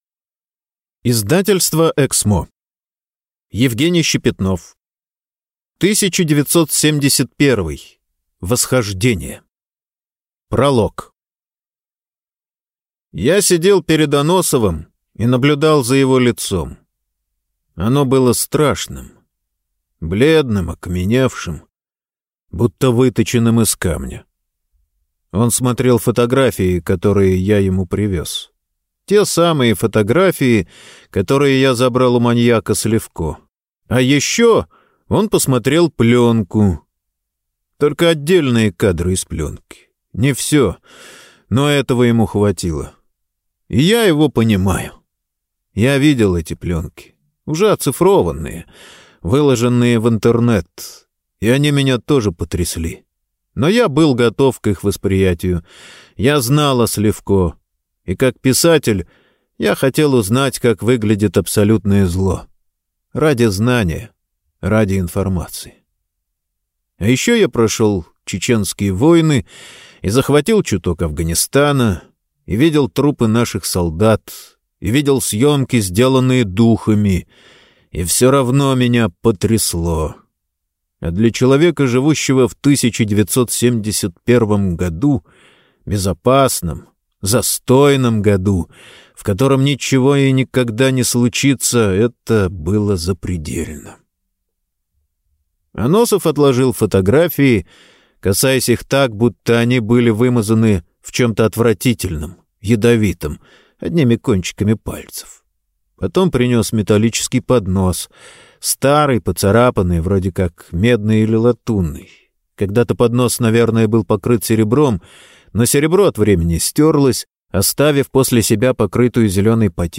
Прослушать фрагмент аудиокниги 1971. Восхождение Евгений Щепетнов Фэнтези Фантастика Попаданцы Произведений: 43 Скачать бесплатно книгу Скачать в MP3 Скачать в TXT Скачать в PDF Скачать в EPUB Вы скачиваете фрагмент книги, предоставленный издательством